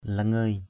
langey.mp3